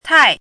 怎么读
tài